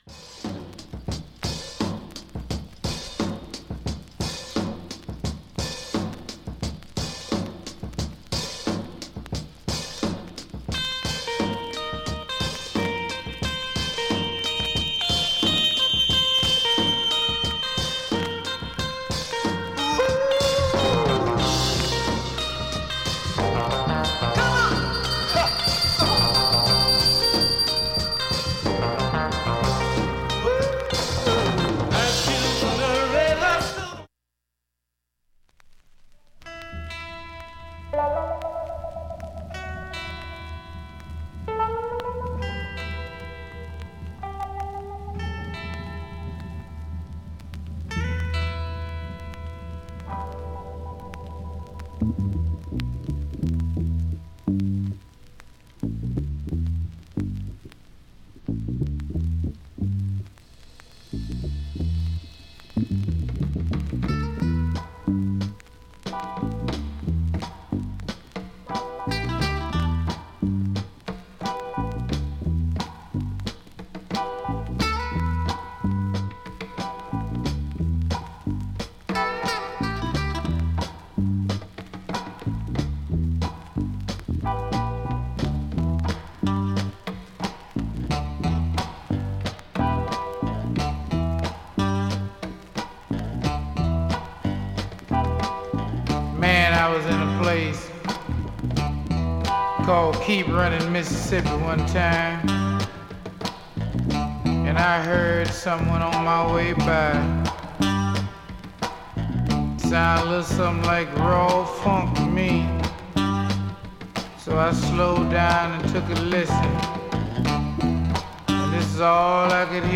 目立つプツ、ジりパチなどもありません
曲間チリも極少なく
音質良好全曲試聴済み。